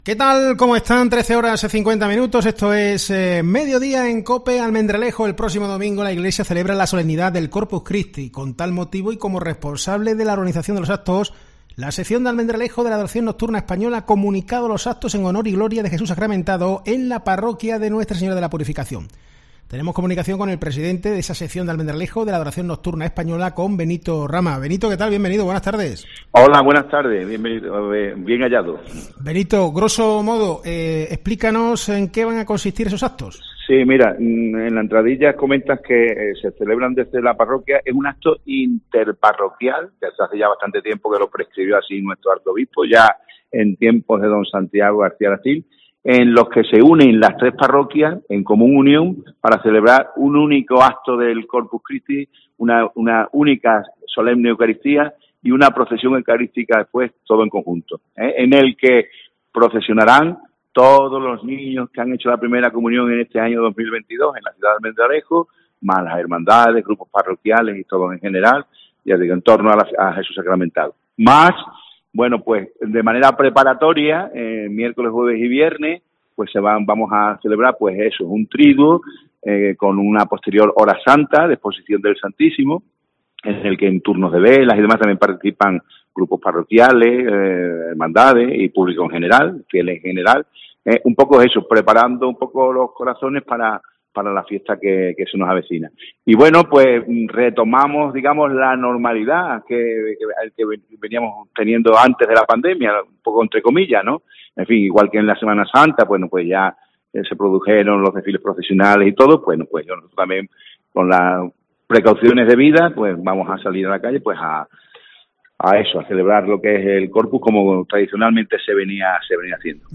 RELIGIÓN